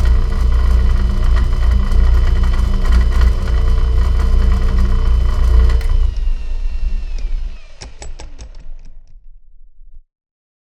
drill-end.wav